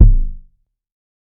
CDK Money Kick.wav